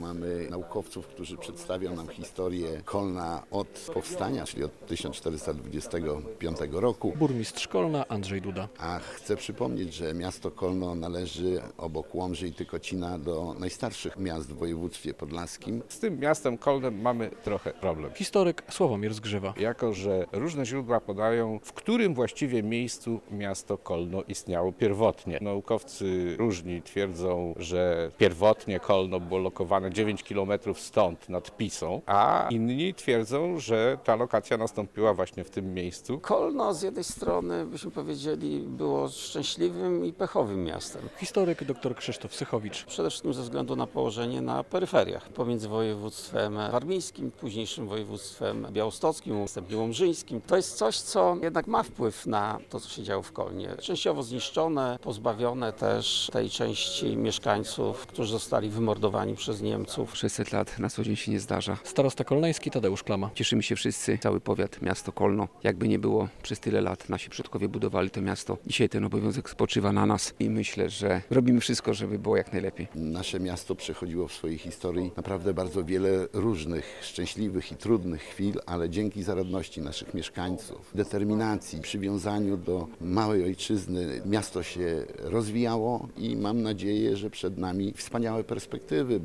Między innymi o tym opowiadali w piątek (6.06) historycy podczas konferencji poświęconej historii miasta.
Konferencja - 600 lat Kolna, 6.06.2025, fot.
Konferencja odbyła się w Kolneńskim Ośrodku Kultury i Sportu.